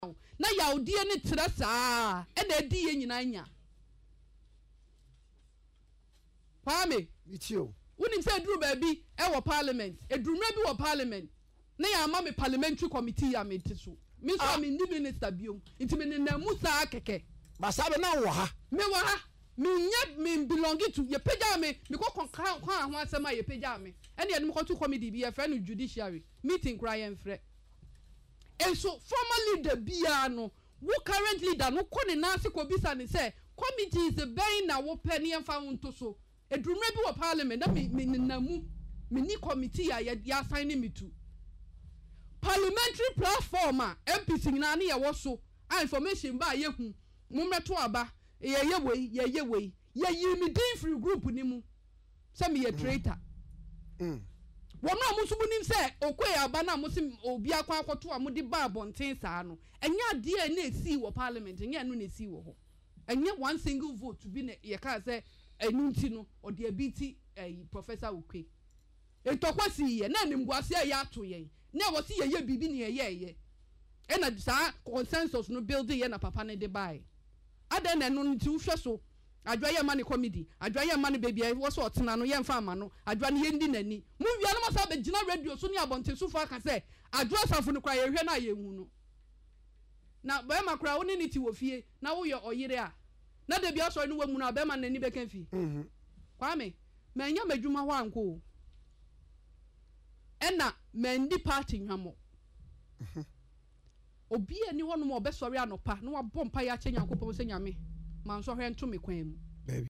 “At one point, I was not given any parliamentary committee. After I complained, I was assigned to the Judiciary Committee, but meetings were never called,” she shared in an interview on Accra-based Okay FM.
Listen to Adwoa Safo in the audio attached above: